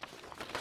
x_enchanting_scroll.1.ogg